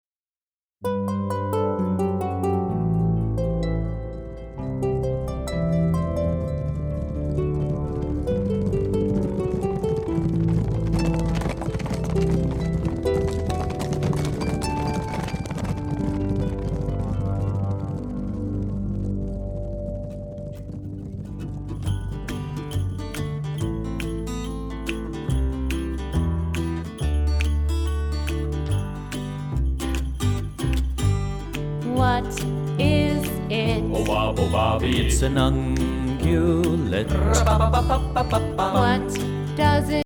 Cheerful songs jubilantly performed